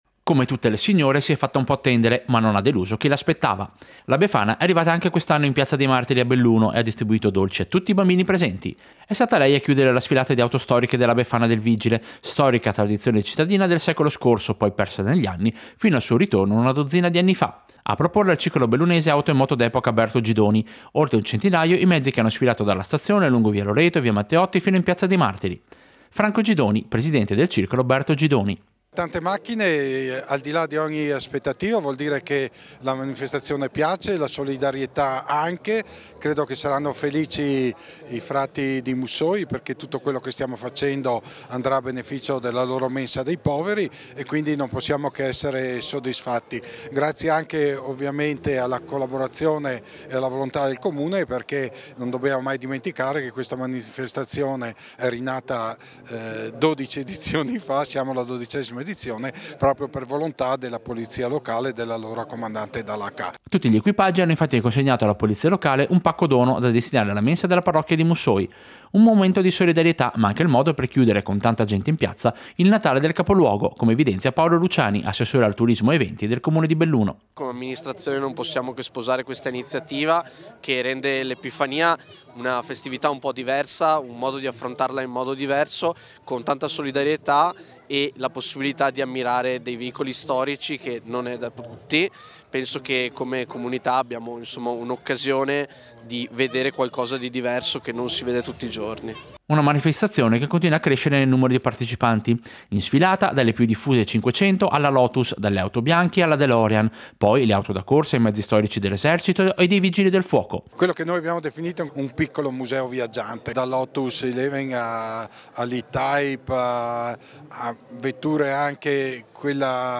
Servizio-Befana-del-vigile-Belluno-2025.mp3